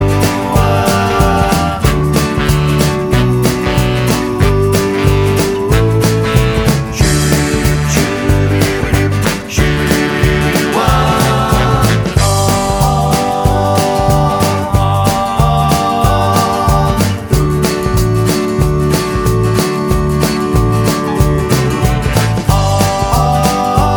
Original Key Of D Rock 'n' Roll 2:57 Buy £1.50